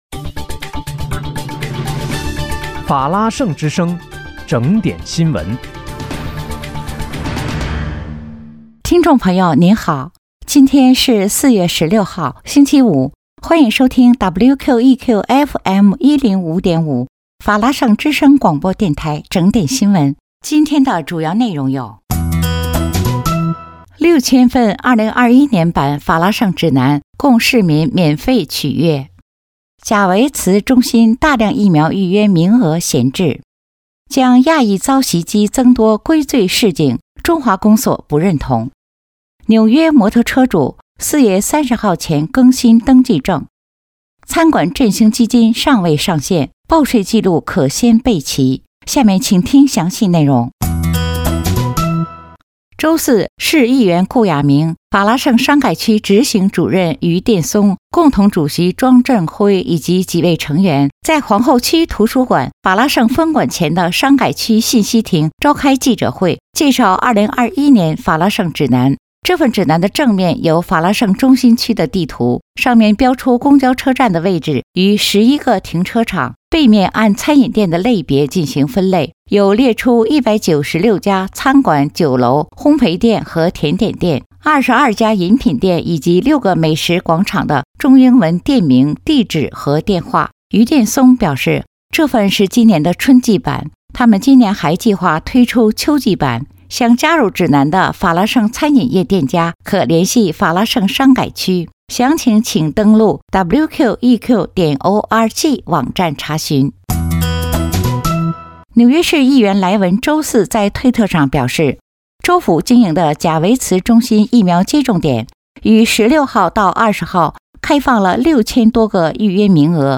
4月16日（星期五）纽约整点新闻